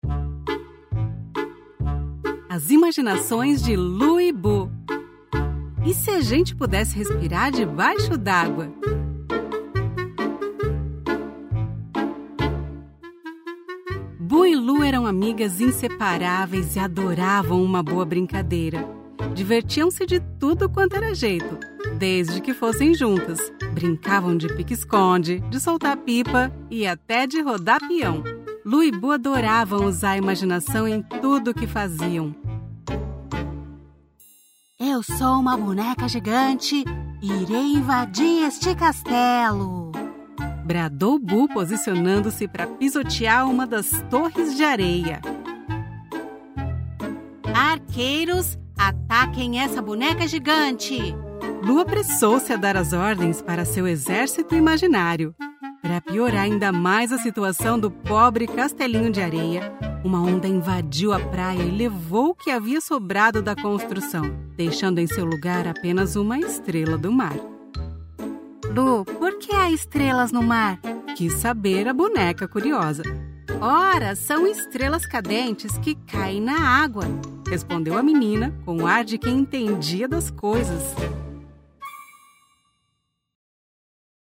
Animation
Her voice is pleasant and versatile and can help you create a unique and captivating listening experience for your audience.
I have a professional home studio with all the bells and whistles.